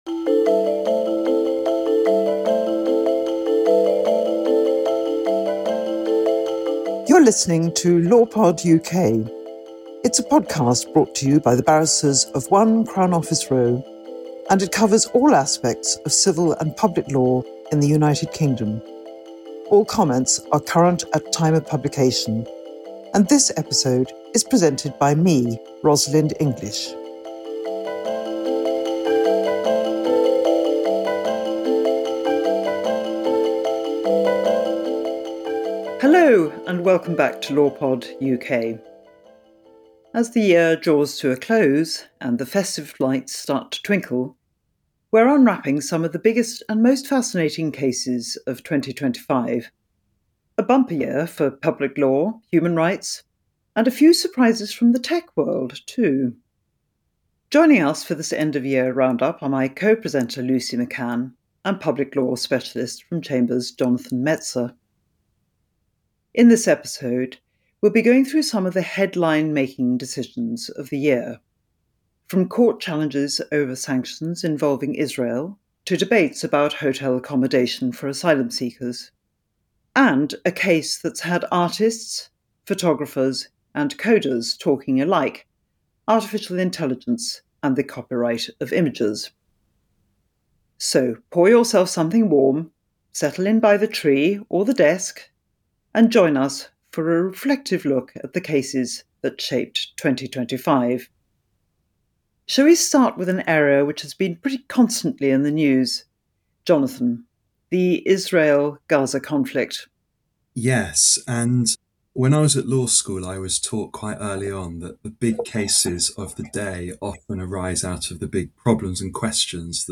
Join the Law Pod team as we discuss a range of cases decided at all levels in the courts in 2025 with important implications for the future.